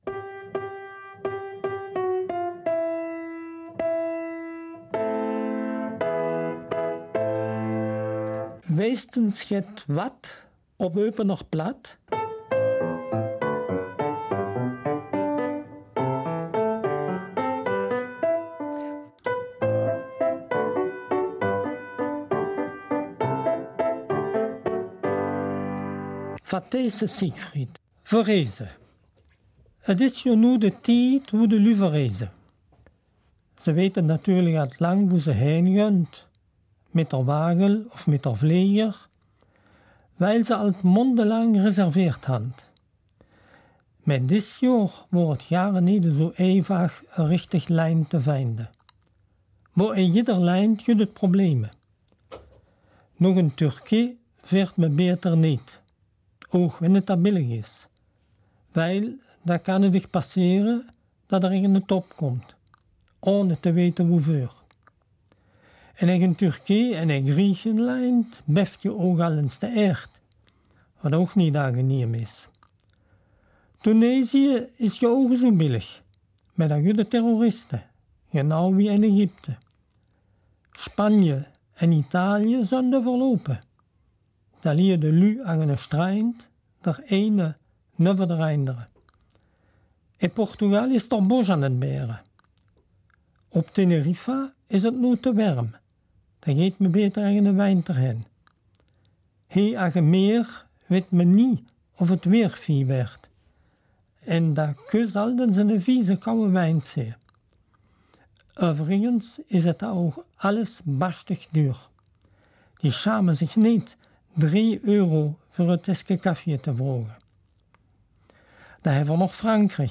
Passend zum Thema wird es auch zwei Lieder und zwei ''Vertélltjére'' geben.